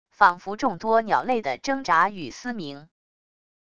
仿佛众多鸟类的挣扎与嘶鸣wav音频